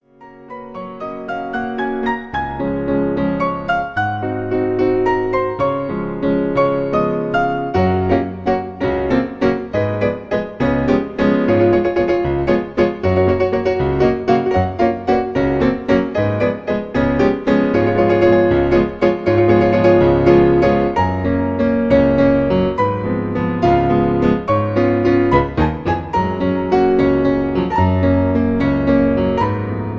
Beschwingtes Duett